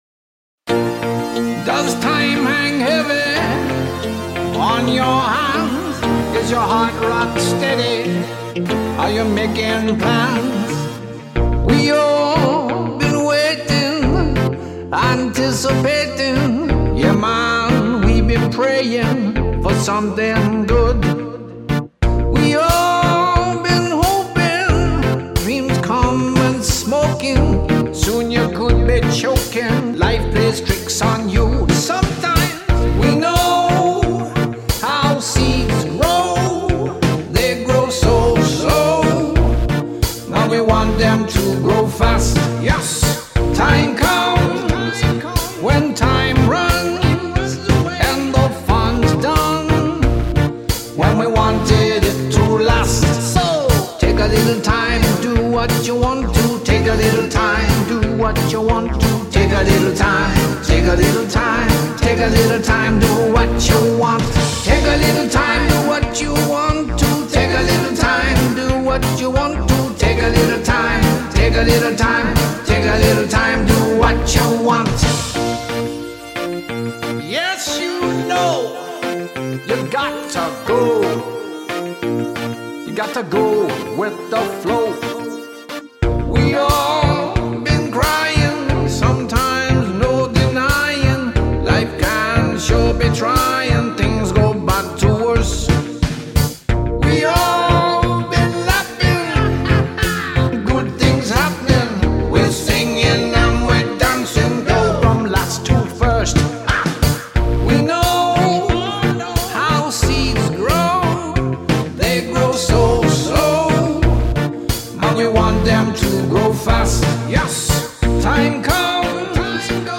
Fun Reggae... from a fan of fun & reggae